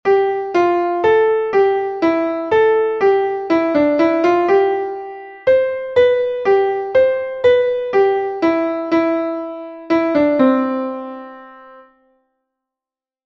- Primaria: unha frase.